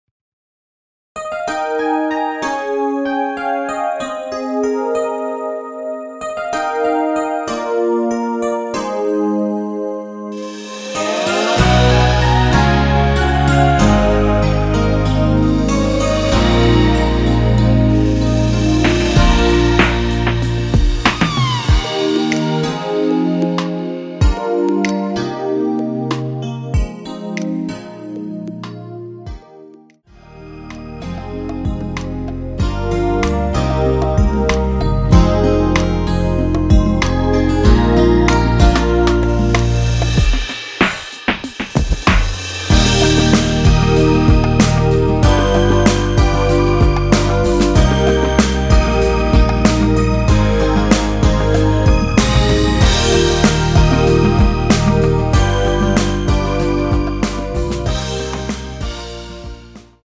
원키에서(-1)내린 MR입니다.
Eb
앞부분30초, 뒷부분30초씩 편집해서 올려 드리고 있습니다.
중간에 음이 끈어지고 다시 나오는 이유는